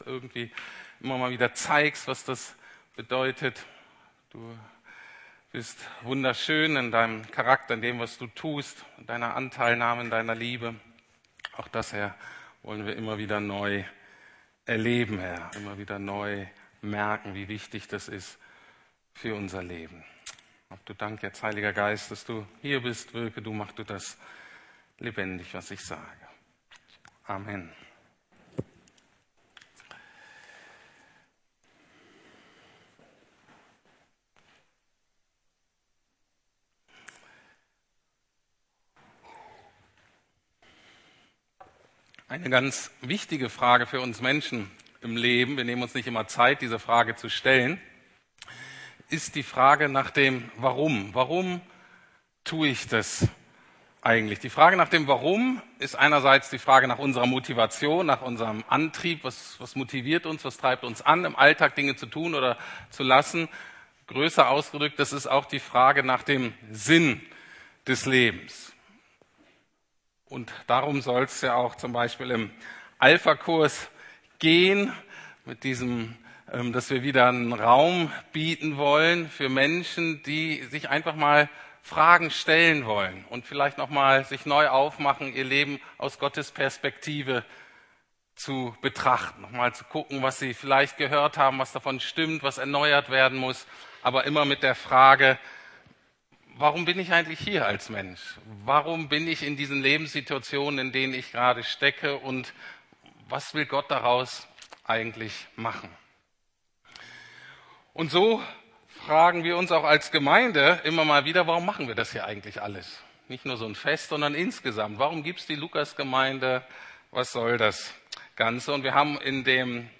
Geht hin in alle Welt und liebt Gott und die Menschen ~ Predigten der LUKAS GEMEINDE Podcast